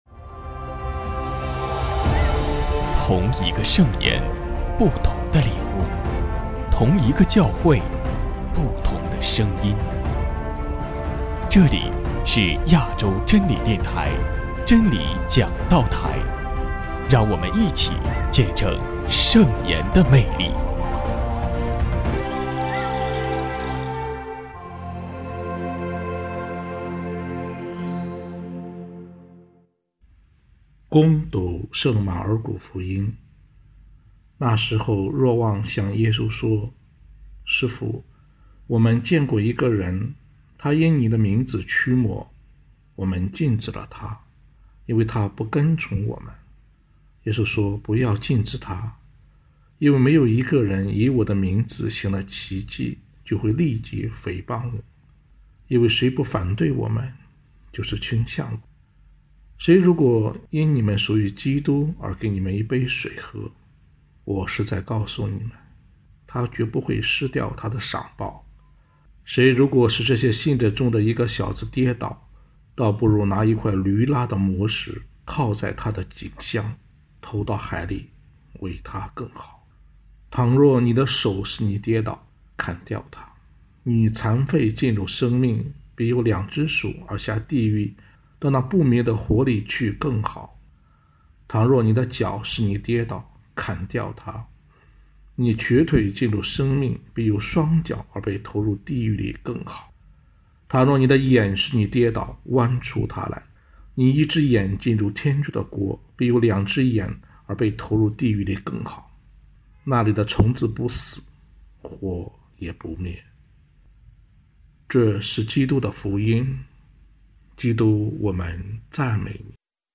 证道